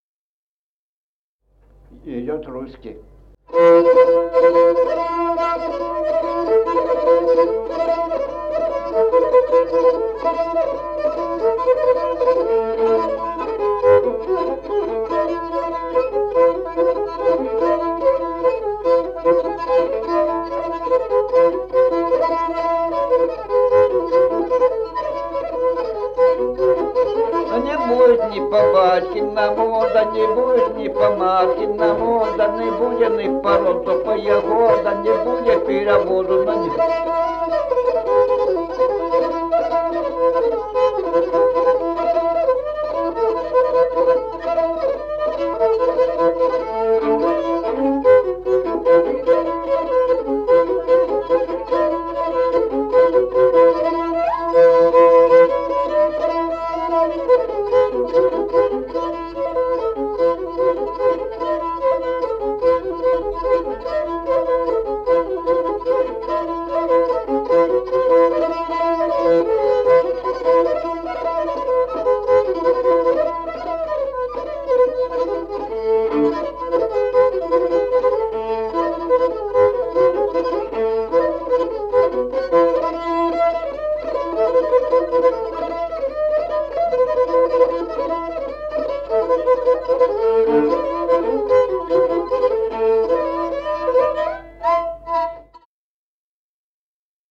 Музыкальный фольклор села Мишковка «Русский», репертуар скрипача.